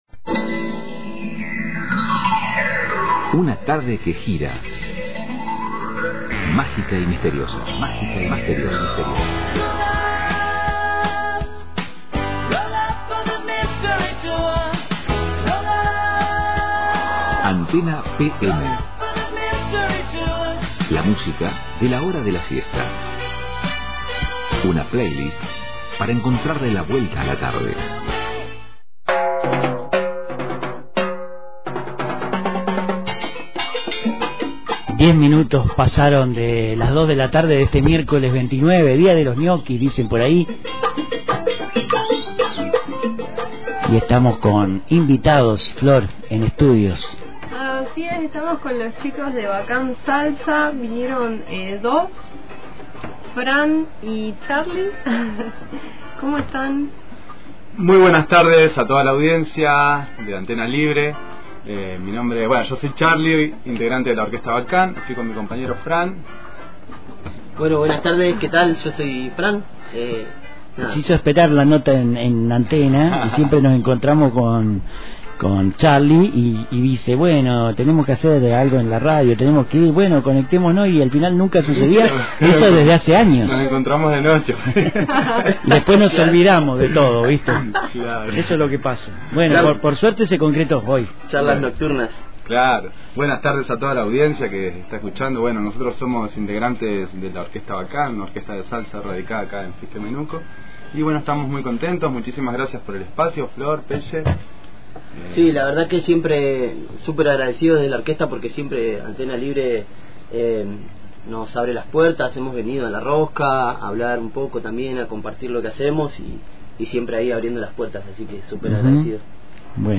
visitaron los estudios de la radio para contarnos del concierto de Bacán, la agrupación con la que vienen tocando en distintos escenarios de la región. En esta oportunidad estrenarán nuevas canciones de cosecha propia en las que vienen trabajando desde hace tiempo, puliendo detalles e incorporando arreglos, como dicen ellos, para que el producto final sea bueno y genere algo lindo en la gente.